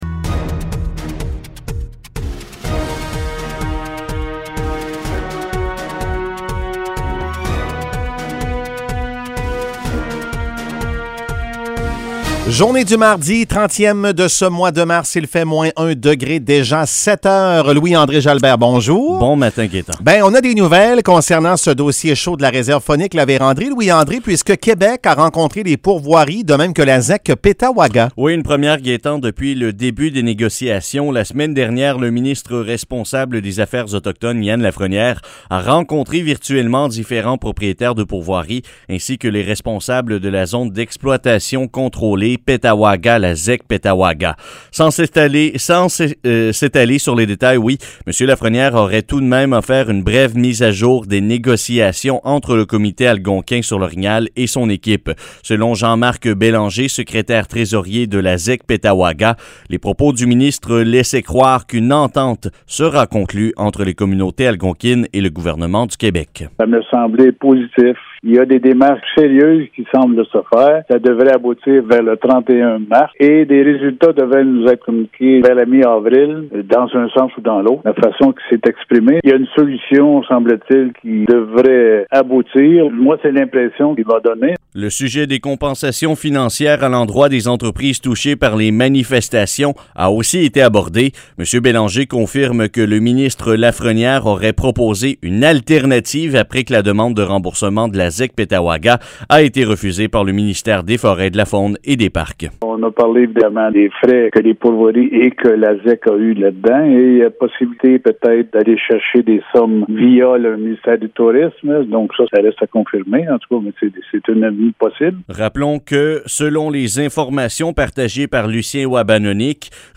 Nouvelles locales - 30 mars 2021 - 7 h